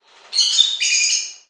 parrot.mp3